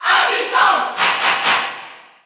Category: Crowd cheers (SSBU) You cannot overwrite this file.
Villager_Female_Cheer_French_NTSC_SSBU.ogg